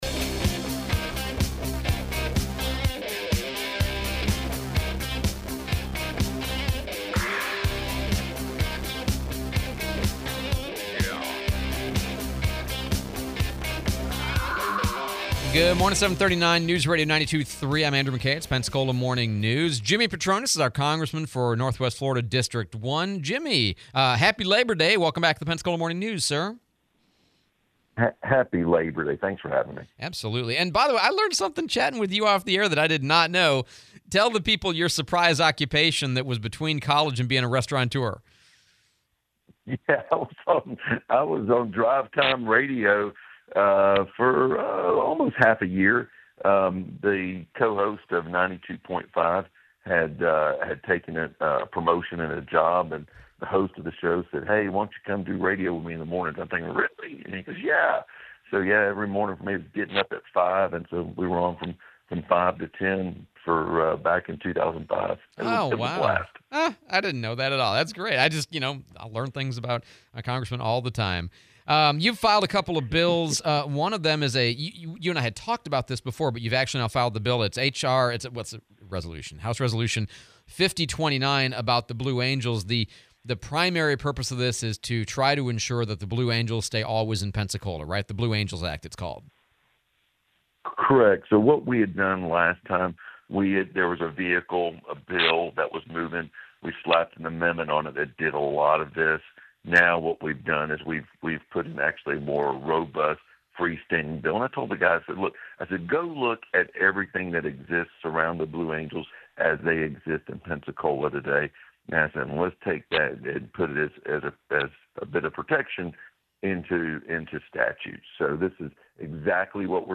09/01/25 Congressman Patronis interview